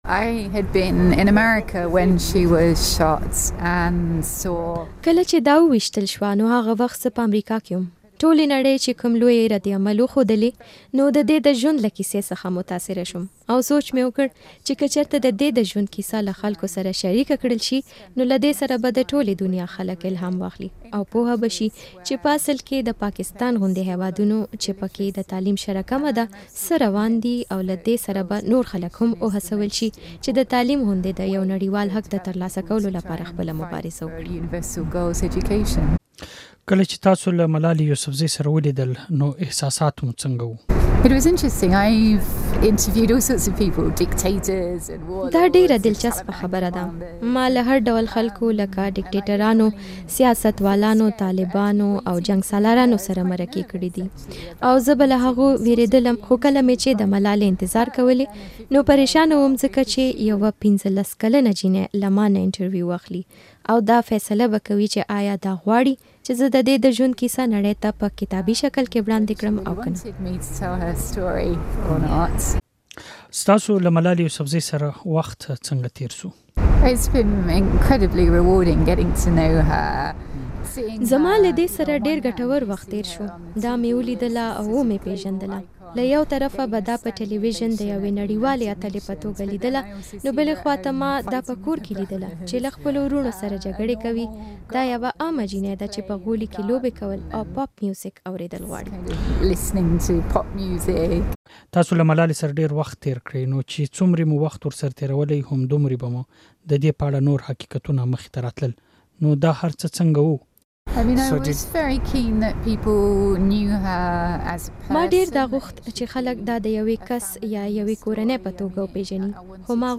د ملالې په اړه له کرسټینا لېمب سره مرکه